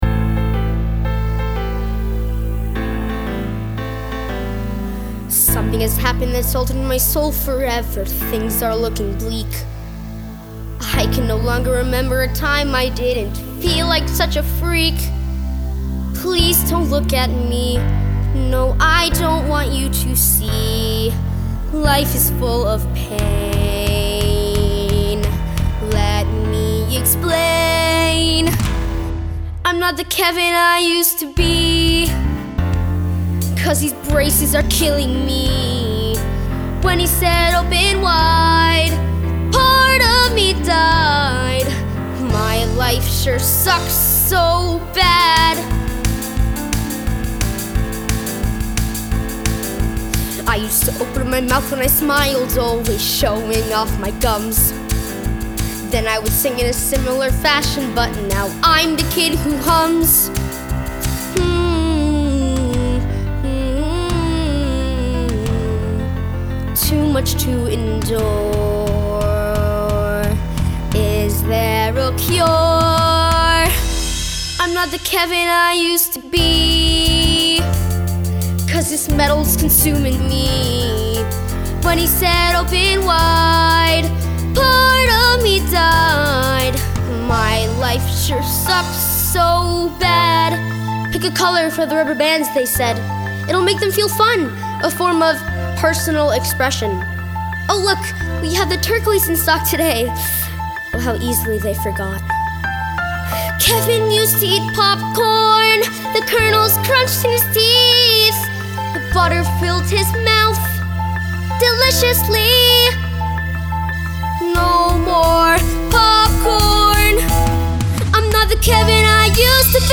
(G#3-C5)
Vocal Demo